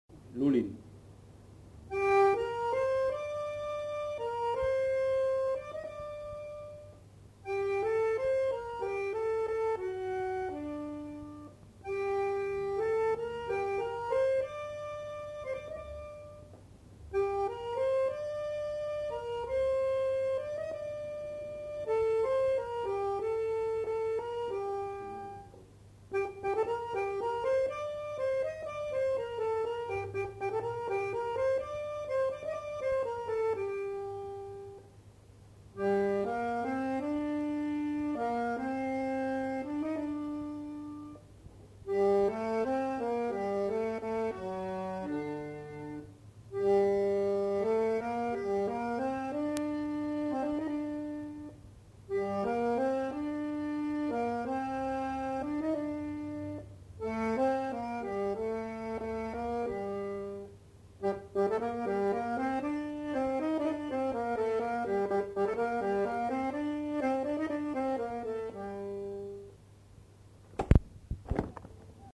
Aire culturelle : Gascogne
Genre : morceau instrumental
Instrument de musique : accordéon chromatique
Notes consultables : Une partie rubato et une autre plus rythmée.